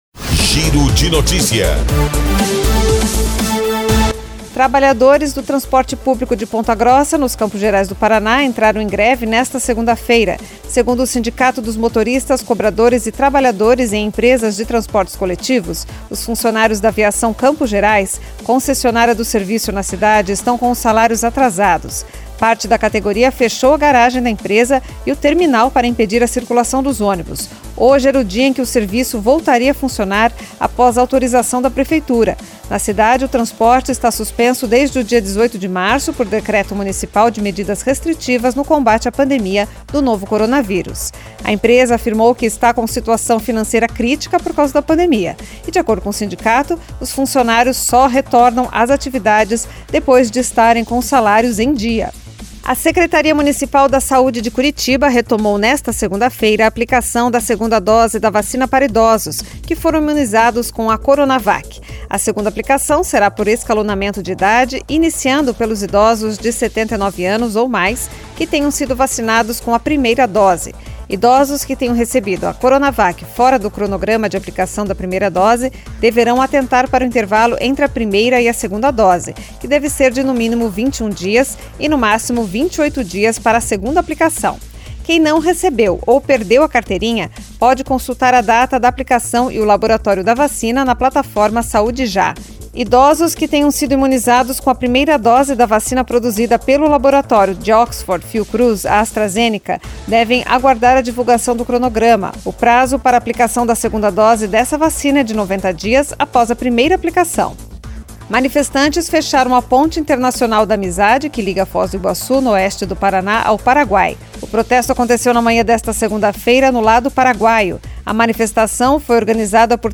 Giro de Notícias Tarde COM TRILHA